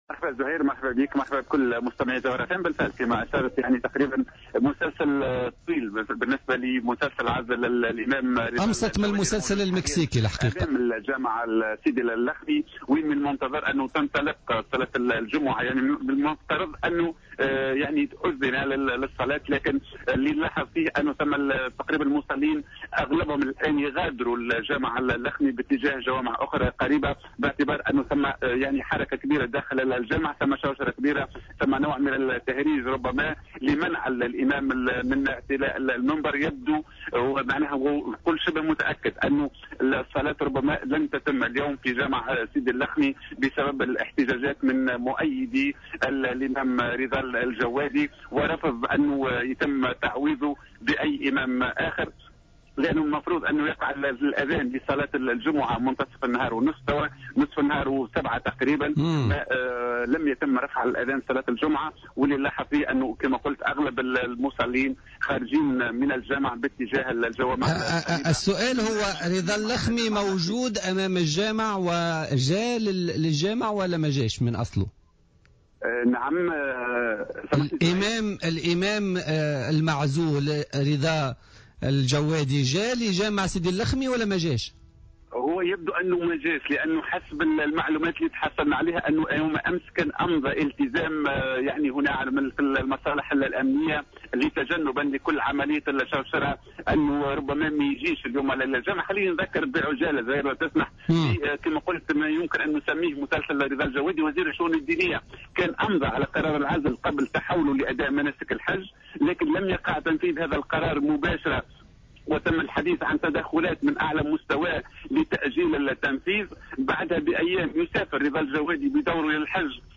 التفاصيل مع مراسلنا بالجهة